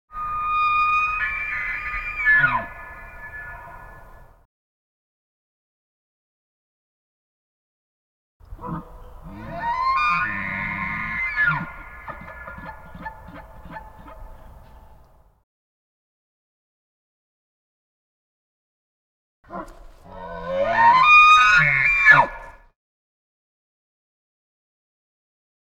جلوه های صوتی
دانلود صدای گوزن از ساعد نیوز با لینک مستقیم و کیفیت بالا